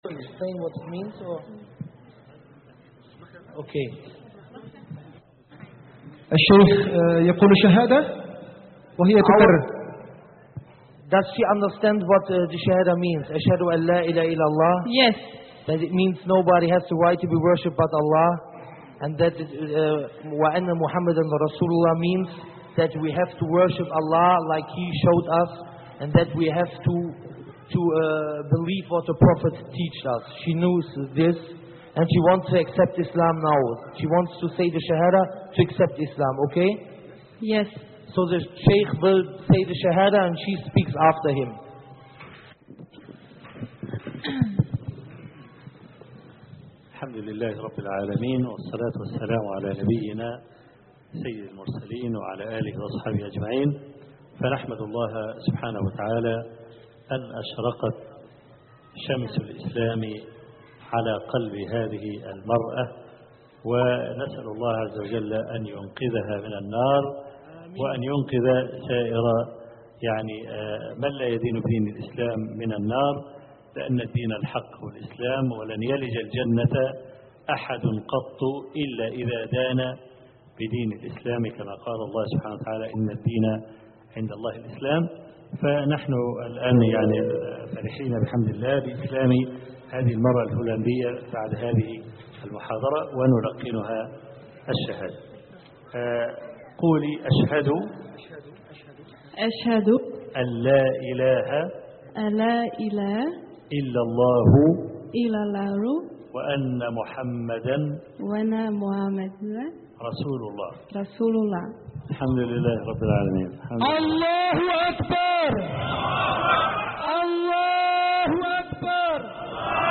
إسلام أخت هولندية بعد محاضرة الشيخ الحويني